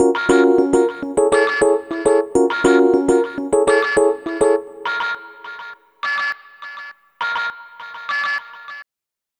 Ala Brzl 1 Piano-D#.wav